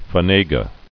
[fa·ne·ga]